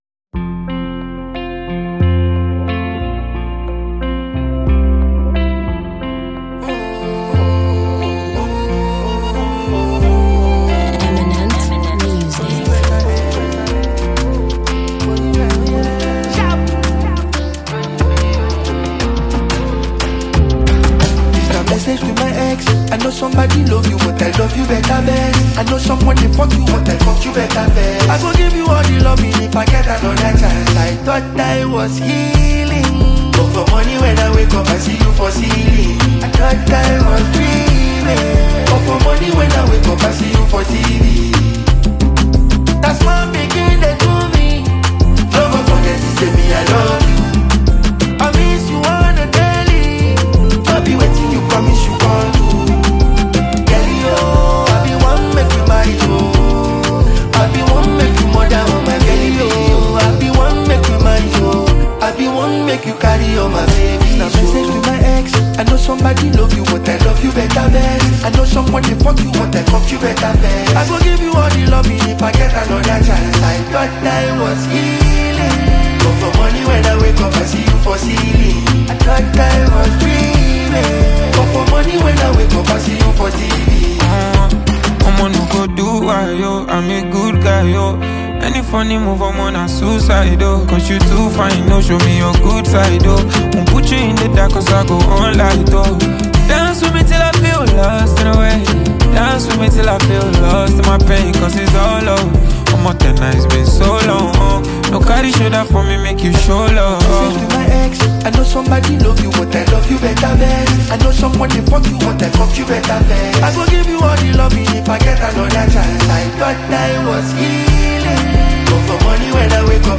a catchy tune
a talented afrobeat singer and lyricist.
soulful vocals